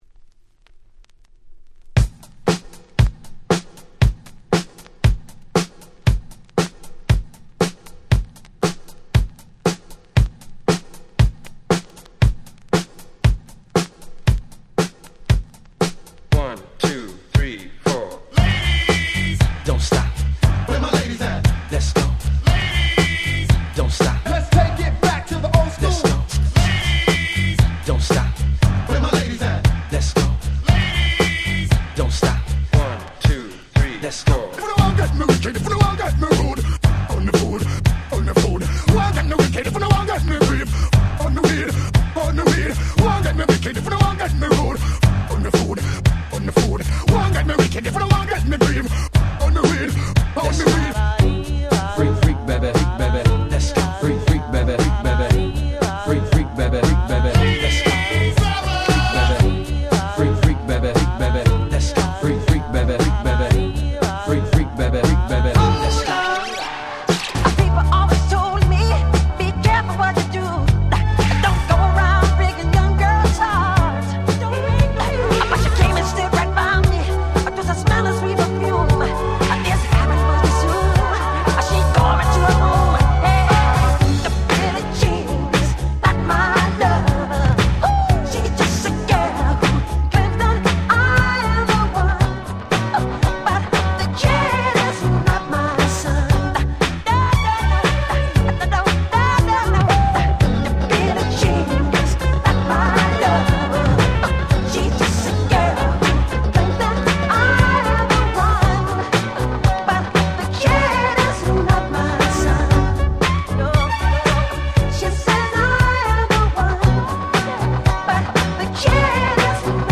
07' Nice Party Tracks / Mash Up !!
00's Hip Hop R&B